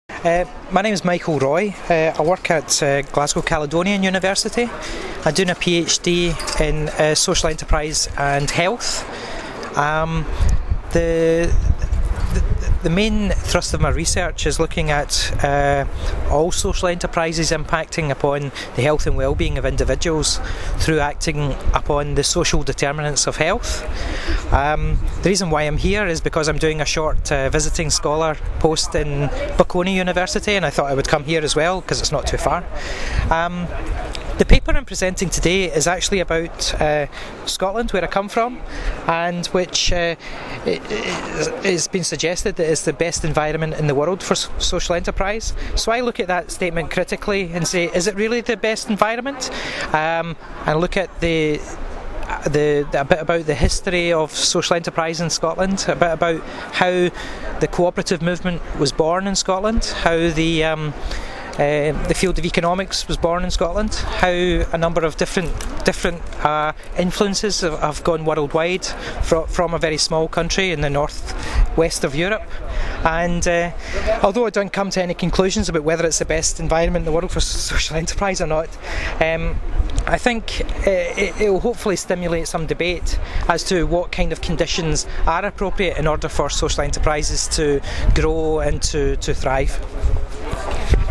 Il Colloquio Scientifico sull’impresa sociale, edizione VII, si è chiuso la scorsa settimana a Torino.
A ricordo di alcuni dei loro interventi, delle brevi audio interviste mordi e fuggi sui loro temi di ricerca.